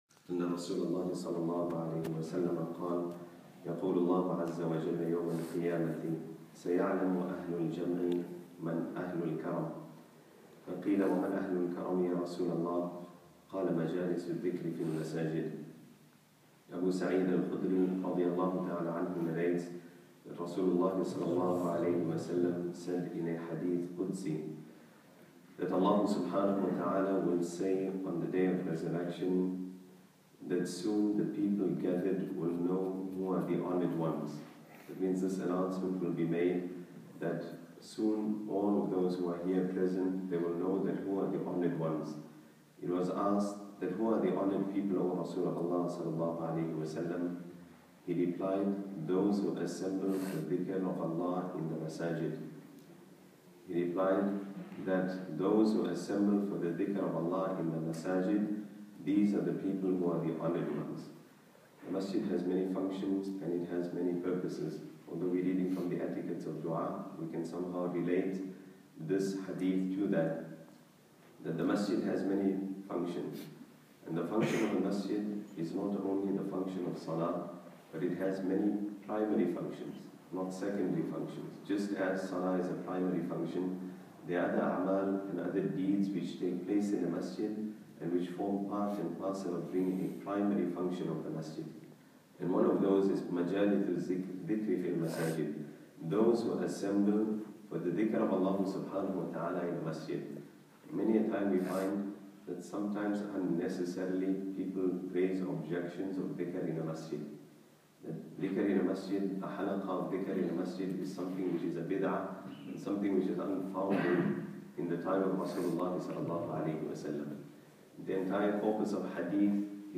Fajr Majlis